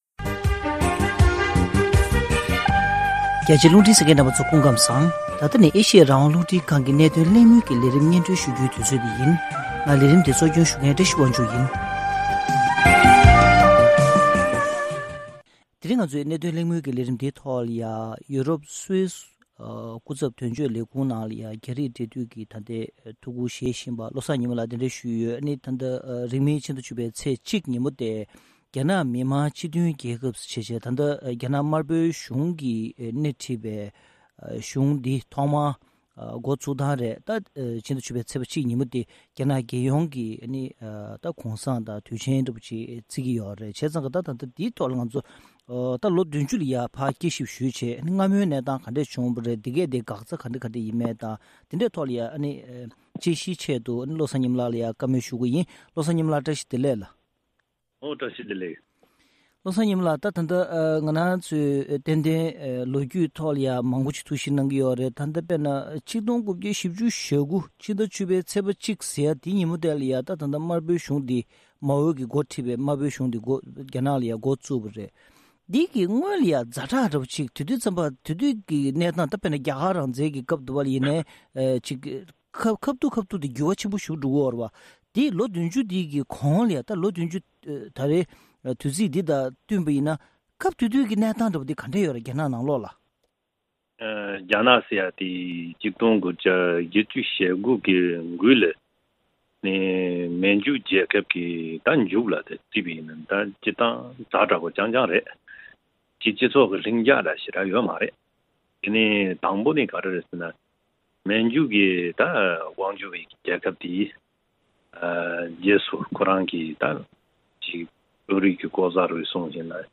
༄༅༎ཐེངས་འདིའི་གནད་དོན་གླེང་མོལ་གྱི་ལས་རིམ་ནང་།